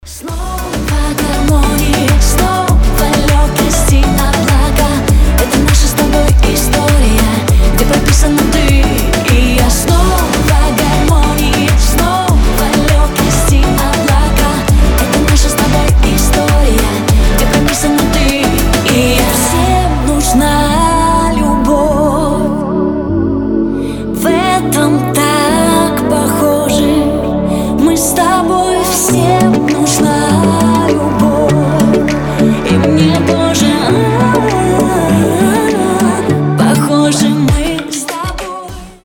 • Качество: 320, Stereo
поп
позитивные
женский вокал
добрые
легкие